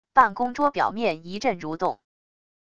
办公桌表面一阵蠕动wav下载